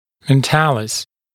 [men’tæləs][мэн’тэлэс]подбородочная мышца